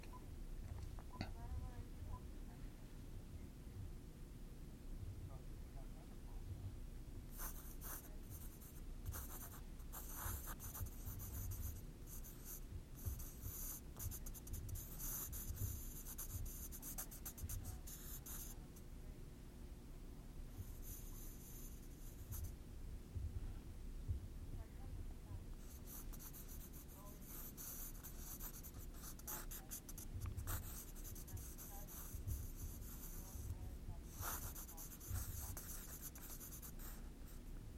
描述：在纸上的随机铅笔素描。
用U87和ME66录制